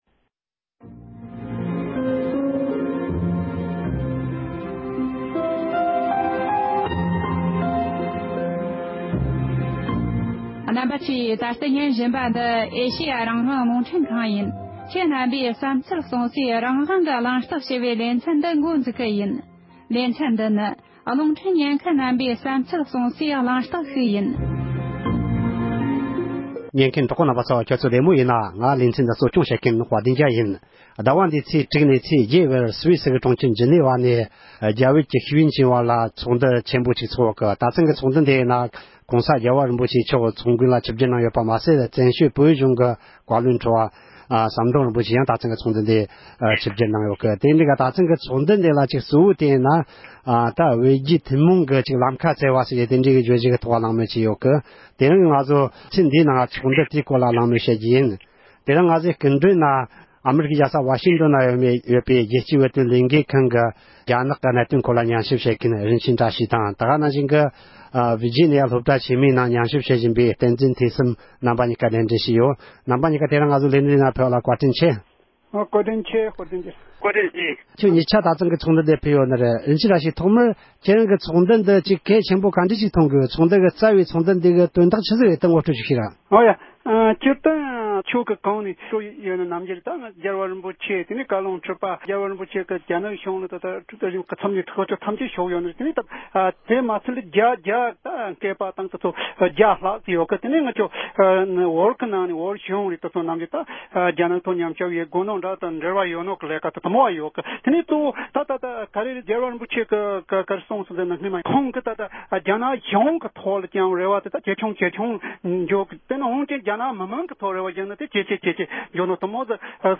ཉེ་ཆར་སུའི་སི་གྲོང་ཁྱེར་ཇི་ནི་བ་ནང་བསྐོང་ཚོགས་གནང་བའི་རྒྱལ་སྤྱིའི་རྒྱ་བོད་ལྷན་ཚོགས་དེའི་སྐོར་ཚོགས་བཅར་བས་འགྲེལ་བརྗོད་གནང་བ།
གླེང་མོལ